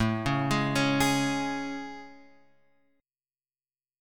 AM7 chord {5 4 6 6 x 4} chord